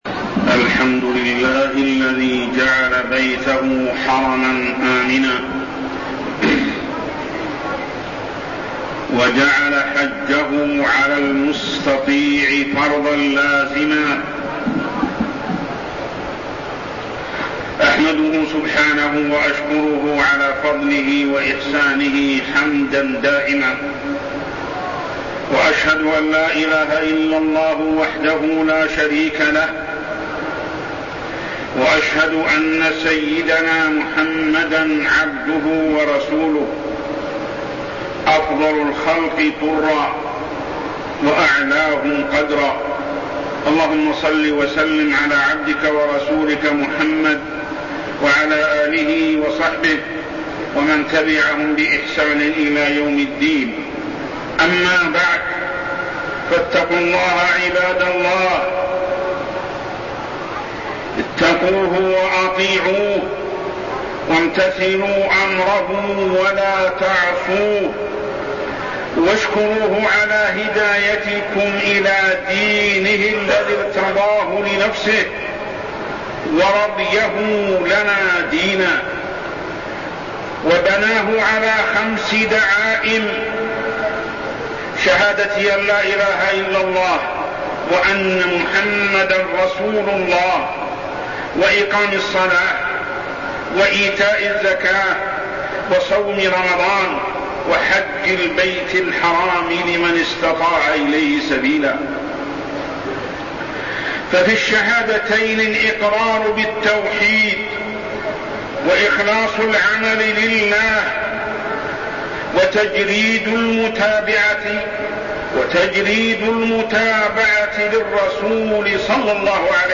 تاريخ النشر ٣ ذو الحجة ١٤١٤ هـ المكان: المسجد الحرام الشيخ: محمد بن عبد الله السبيل محمد بن عبد الله السبيل أركان الإسلام الخمس